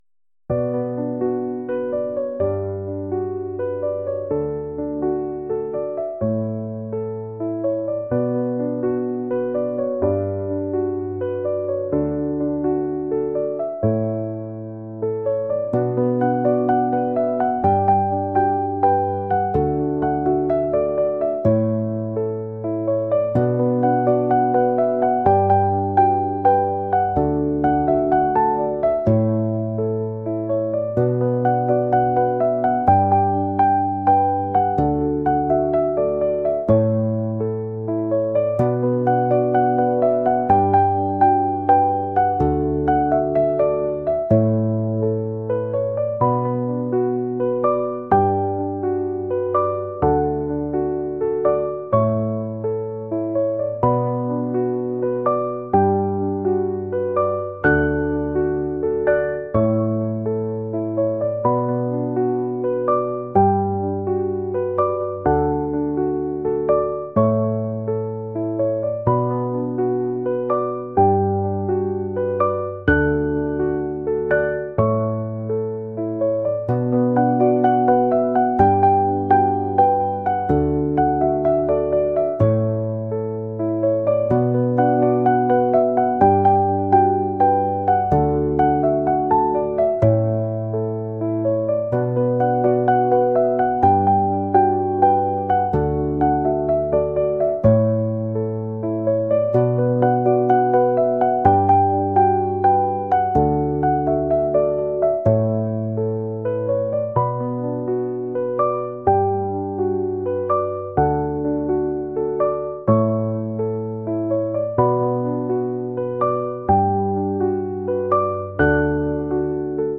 pop | acoustic | folk